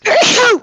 sneeze4.wav